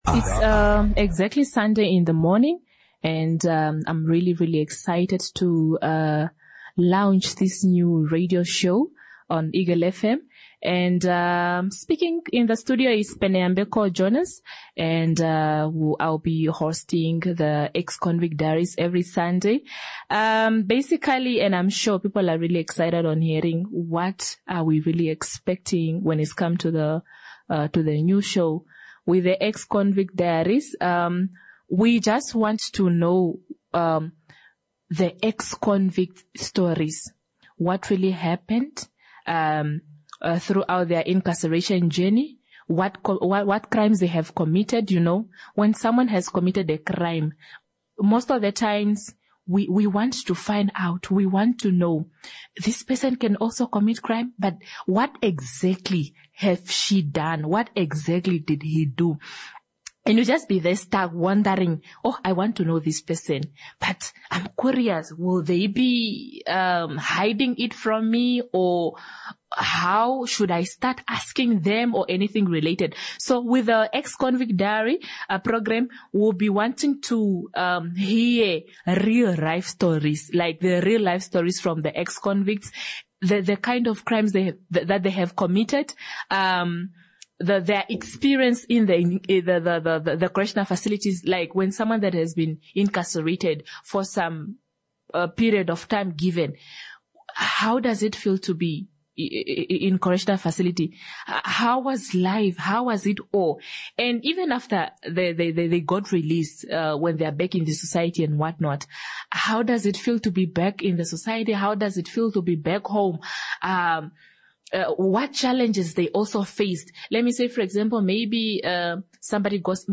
Join us for the official launch of The Ex-Convicts Diaries, a powerful new radio program that brings you real-life stories of those behind bars; the struggles, lessons, and the transformative journeys towards hope and purpose after release.